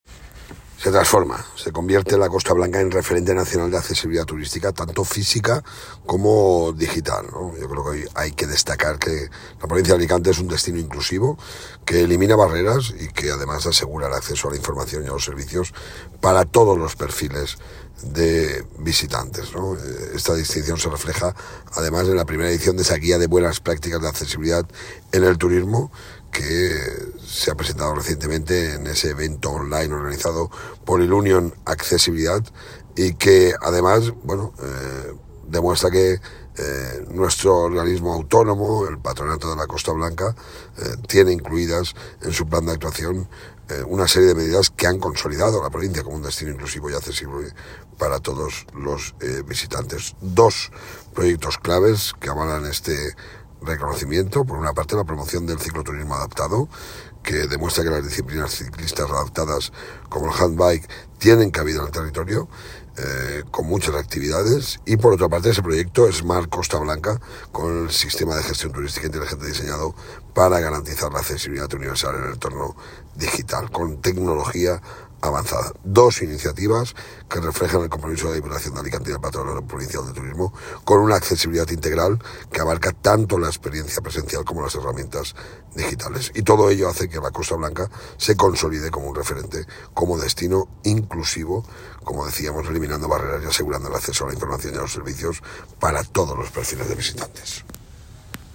Presidente-Guia-turismo-accesible.m4a